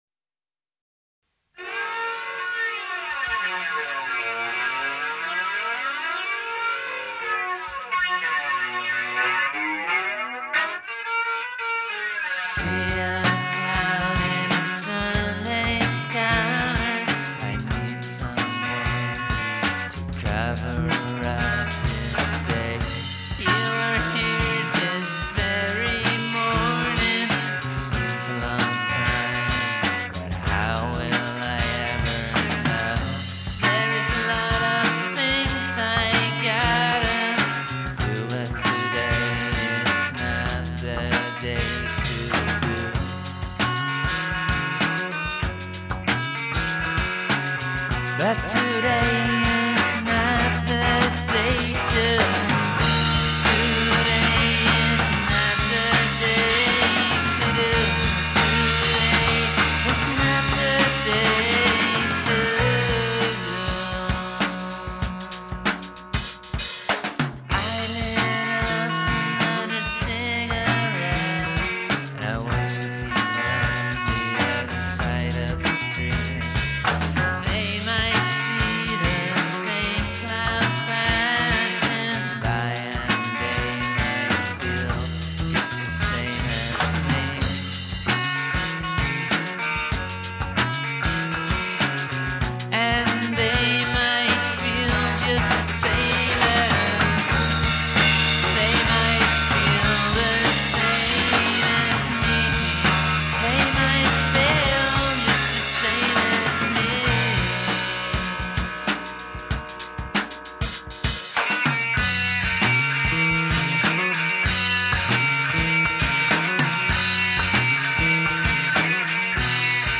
where: recorded at AMP (Amsterdam)
trivia: Try to listen past the flanger